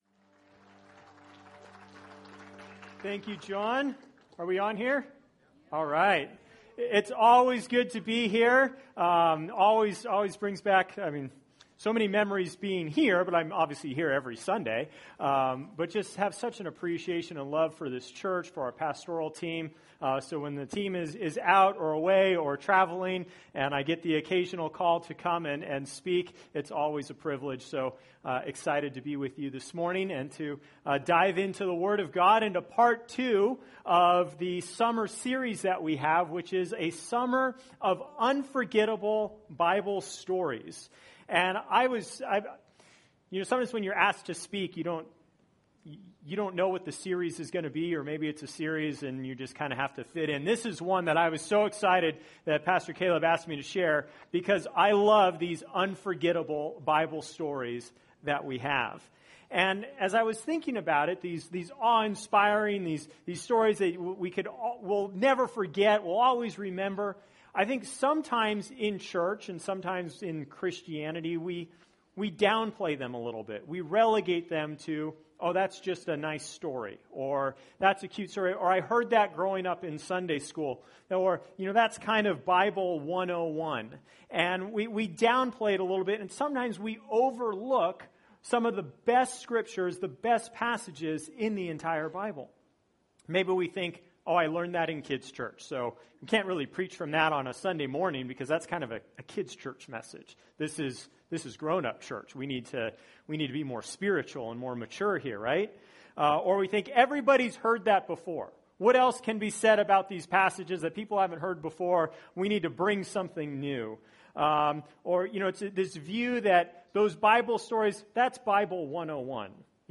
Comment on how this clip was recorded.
Recorded at New Life Christian Center, Sunday, July 15, 2018 at 11 AM.